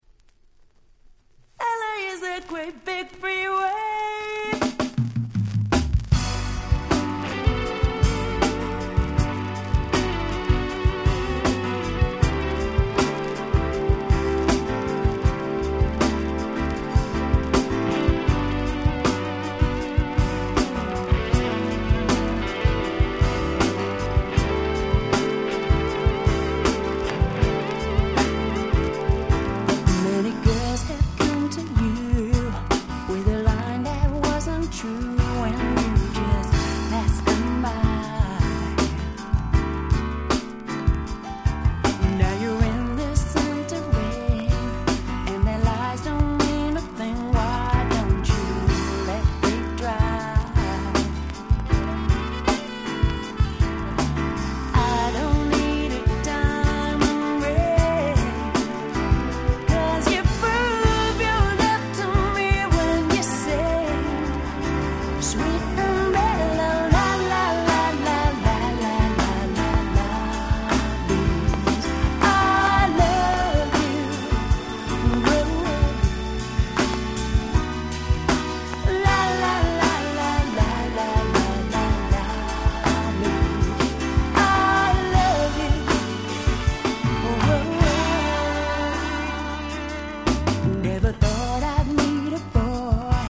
※試聴ファイルは別の盤から録音してございます。